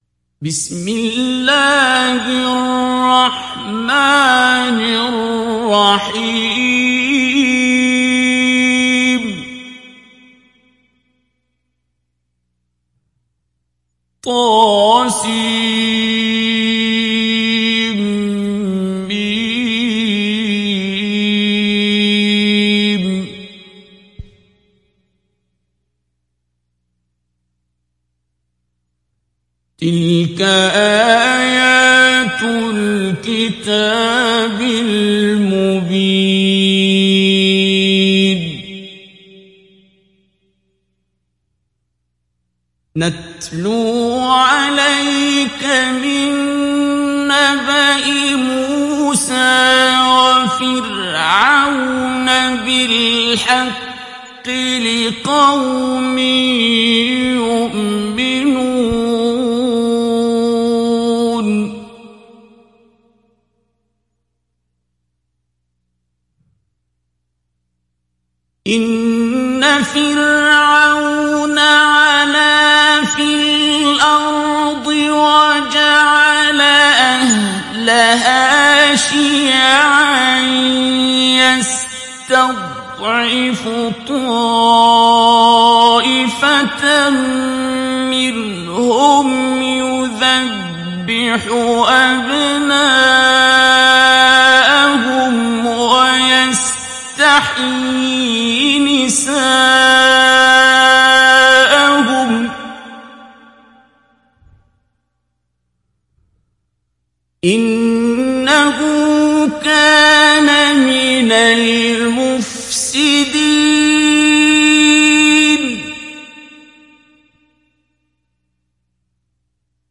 ডাউনলোড সূরা আল-ক্বাসাস Abdul Basit Abd Alsamad Mujawwad